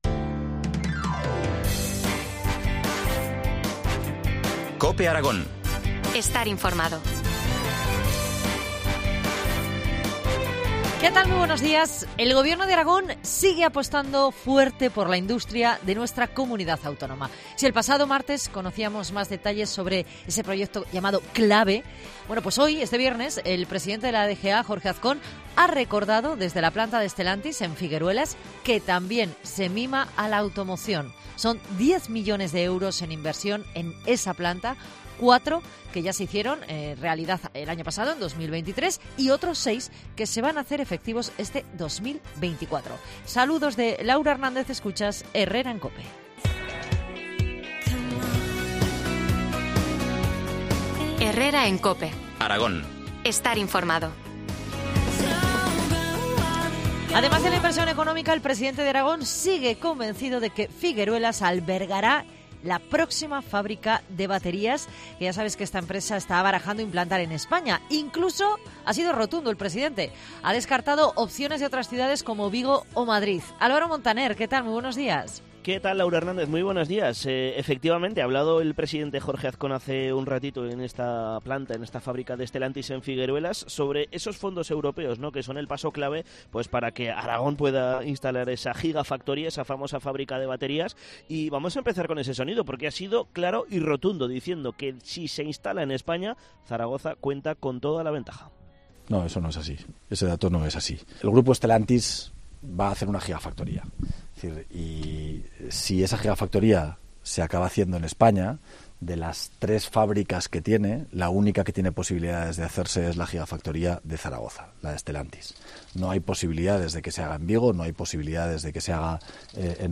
AUDIO: Entrevista del día en COPE Aragón